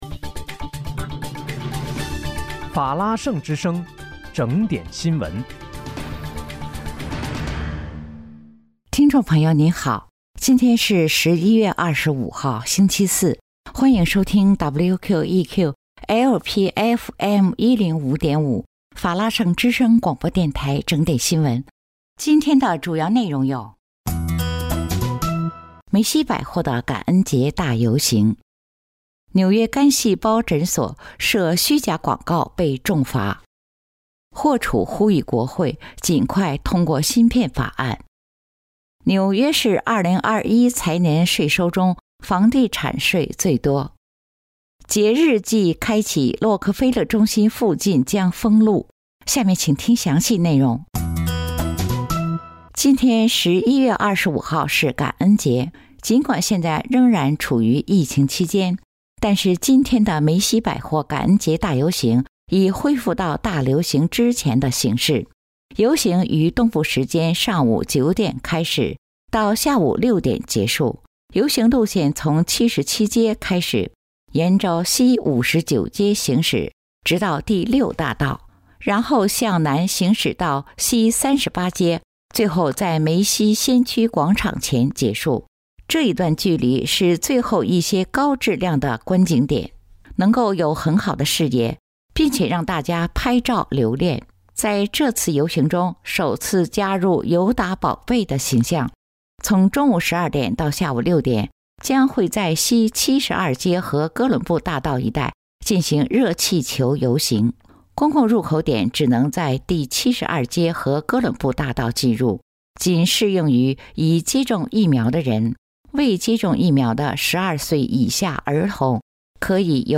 11月25日（星期四）纽约整点新闻
听众朋友您好！今天是11月25号，星期四，欢迎收听WQEQ-LP FM105.5法拉盛之声广播电台整点新闻。